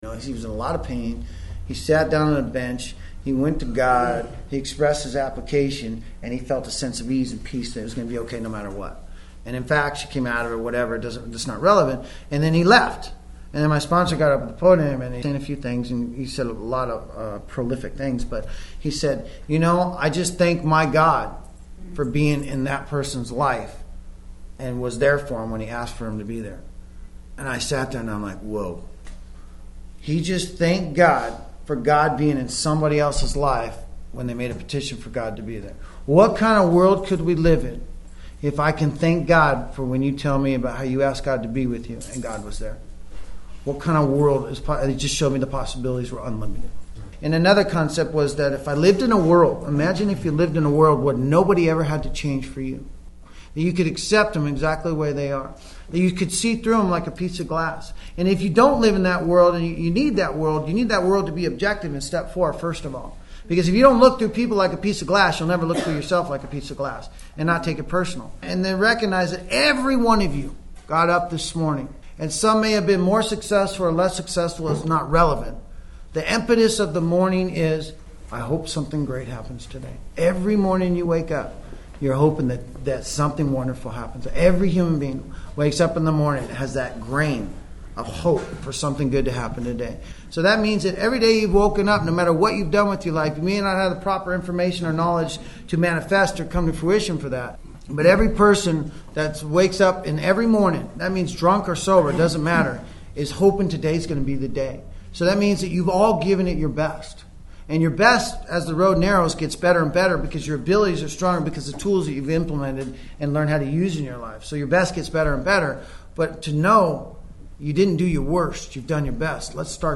This audio archive is a compilation of many years of lecturing.